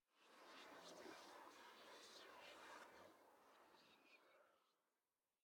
Minecraft Version Minecraft Version latest Latest Release | Latest Snapshot latest / assets / minecraft / sounds / ambient / nether / soulsand_valley / whisper8.ogg Compare With Compare With Latest Release | Latest Snapshot
whisper8.ogg